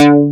MKSBASS3.wav